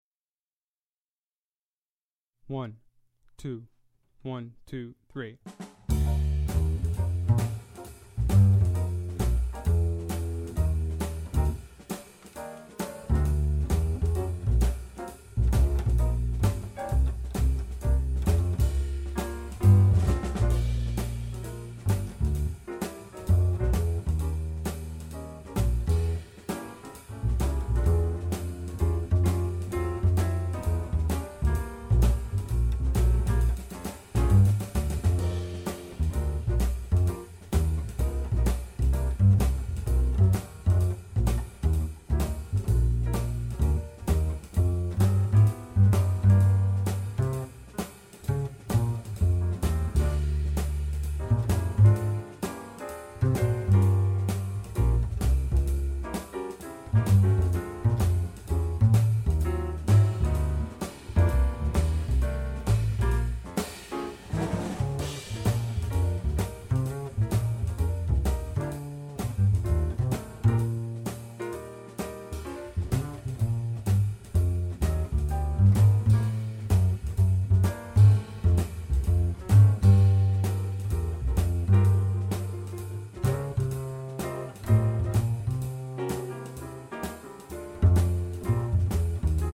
Voicing: Bass